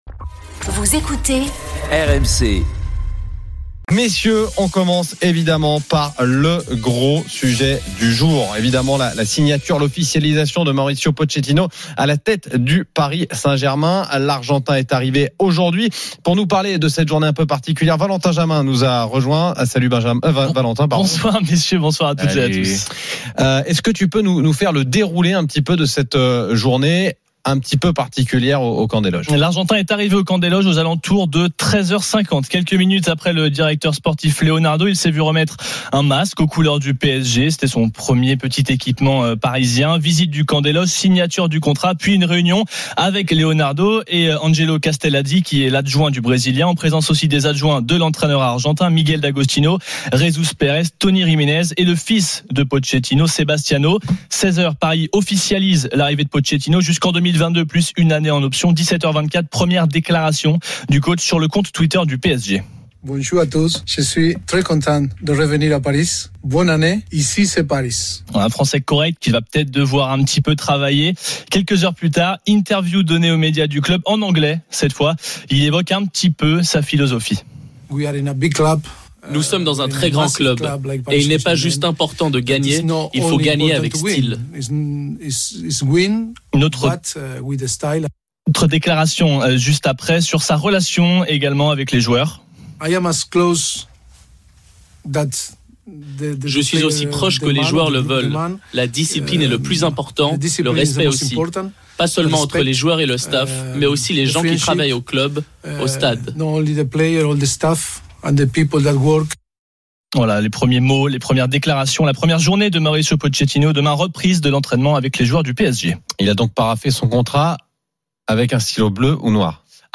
Chaque jour, écoutez le Best-of de l'Afterfoot, sur RMC la radio du Sport !
L'After foot, c'est LE show d'après-match et surtout la référence des fans de football depuis 15 ans !